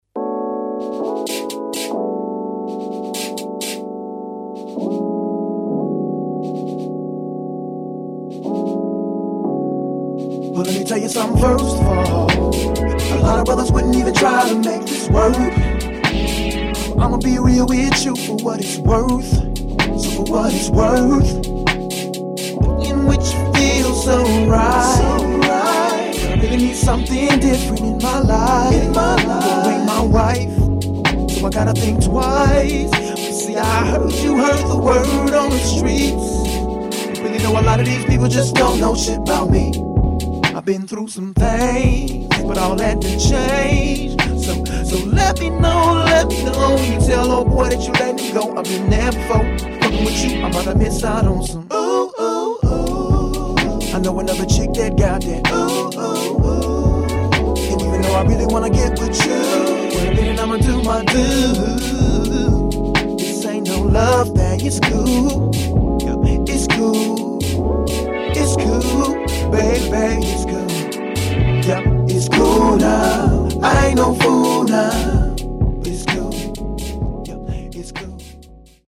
[ JAZZ / SOUL ]